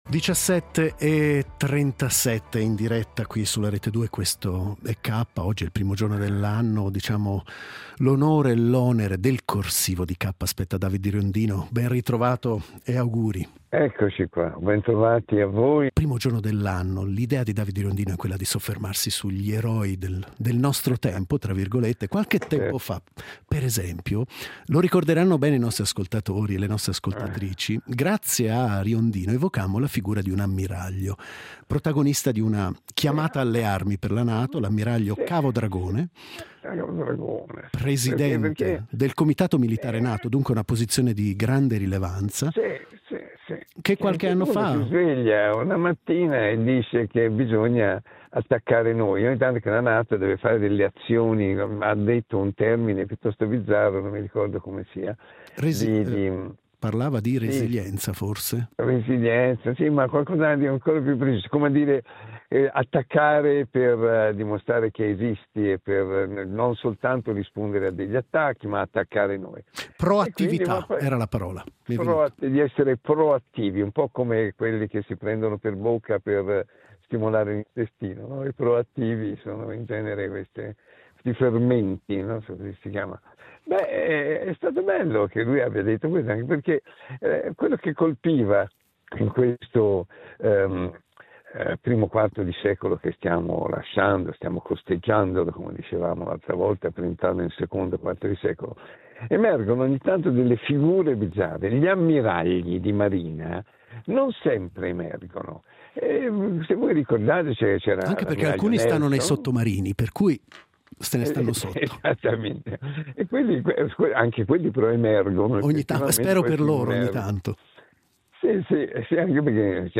L’editoriale del giorno firmato da David Riondino
In questa puntata David Riondino esplora il concetto di “eroi del nostro tempo”. Inizialmente, si concentra sull’ammiraglio Cavo Dragone e la sua idea di “proattività”, presentando anche una canzone generata dall’IA in suo onore.